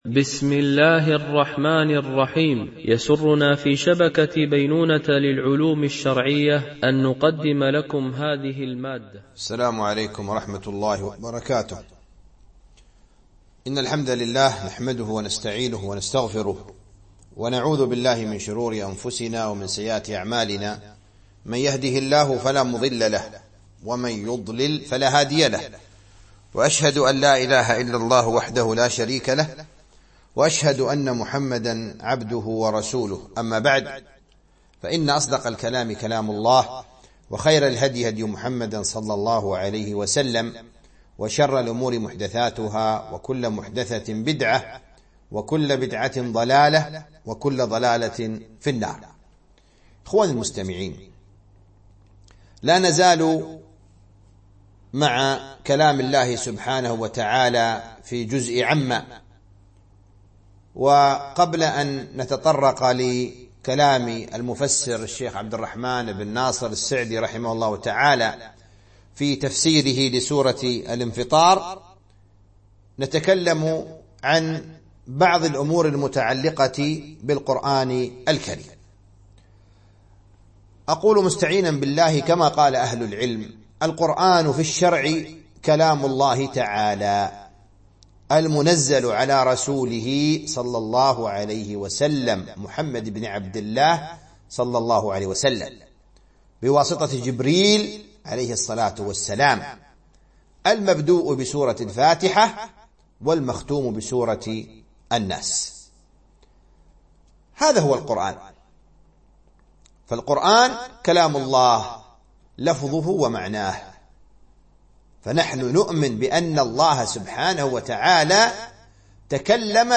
التعليق على تفسير جزء عم من تفسير السعدي - الدرس 4 (سورة الانفطار)